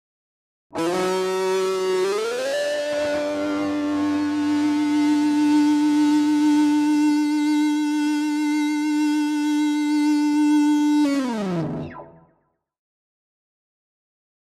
Rock Guitar Distorted FX 6 - Long Sustain Tone 3